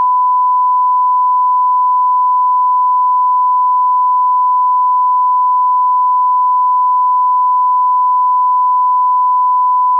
sine_2ch.wav